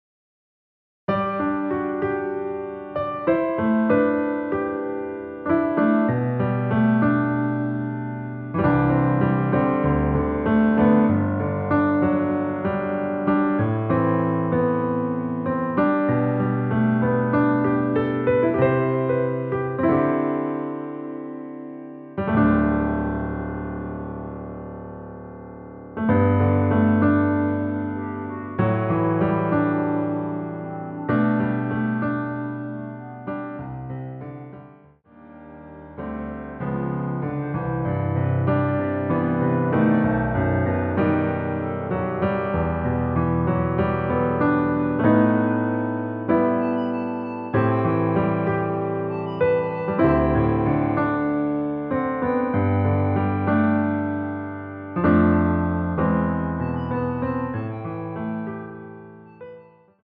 원키에서(-3)내린 멜로디 포함된 MR입니다.
앞부분30초, 뒷부분30초씩 편집해서 올려 드리고 있습니다.
중간에 음이 끈어지고 다시 나오는 이유는